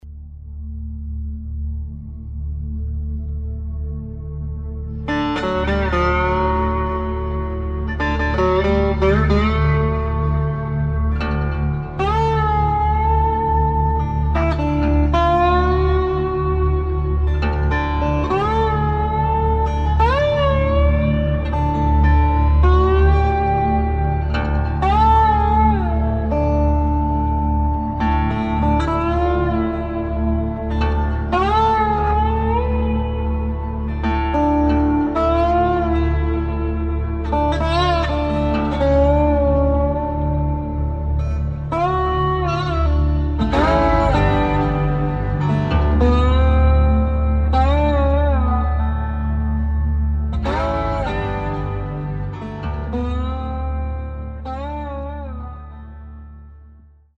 Filmmusik - Landschaften
Musikstil: Western Ambient
Tempo: 65 bpm
Tonart: D-Dur
Charakter: bedrückend, ergreifend
Instrumentierung: Synthesizer, Piano, Slide Gitarre